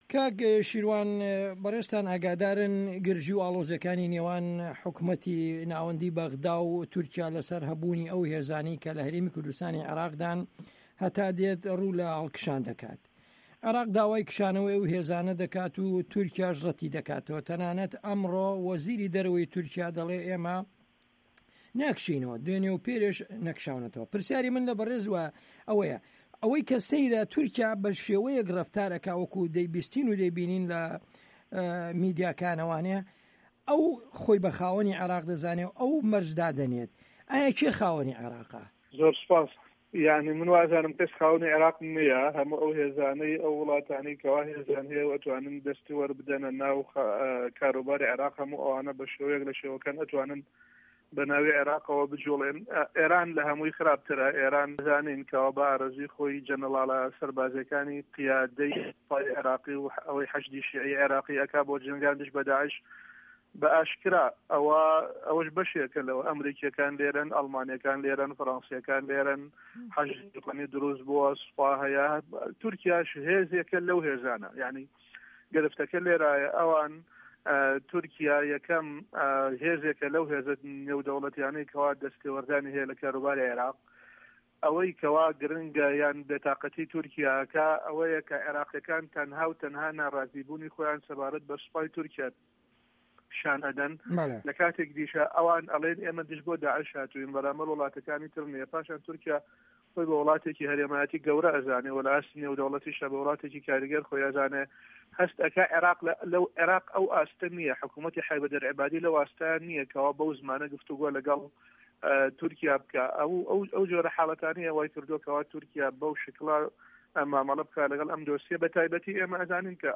عێراق - گفتوگۆکان